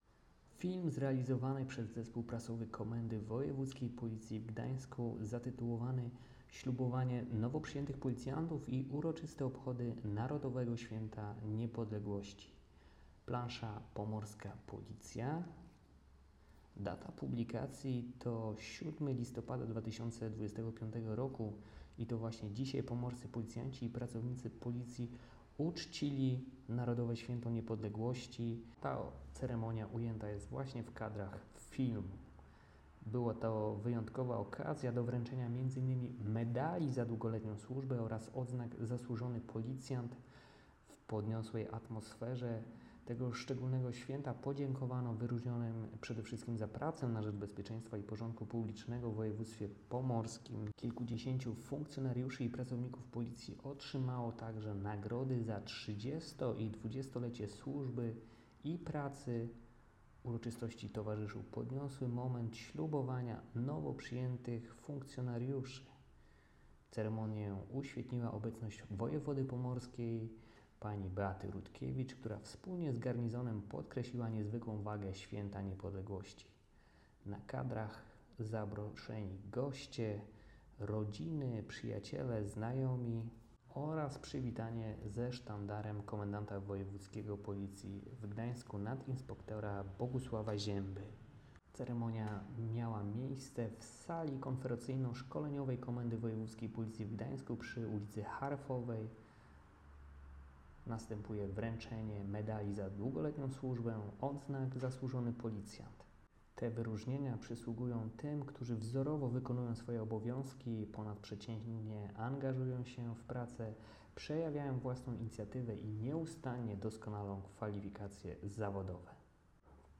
Nagranie audio audiodyskrypcja__1_.m4a